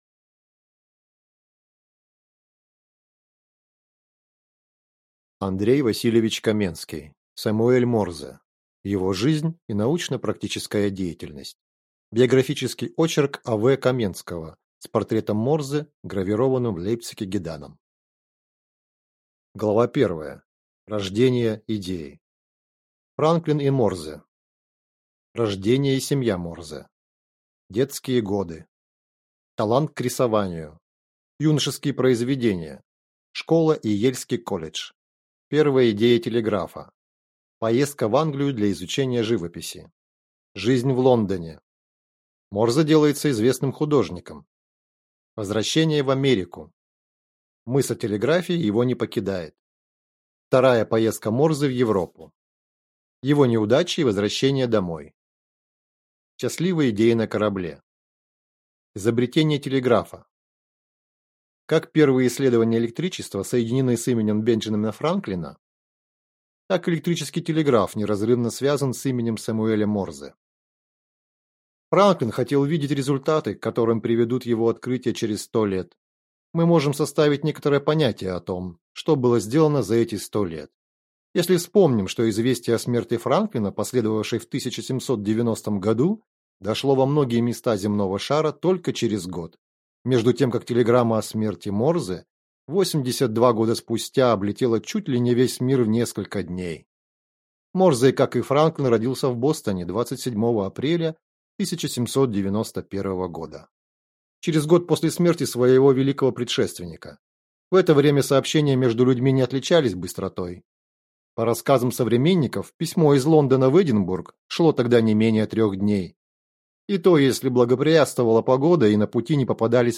Аудиокнига Сэмюэль Морзе. Его жизнь и научно-практическая деятельность | Библиотека аудиокниг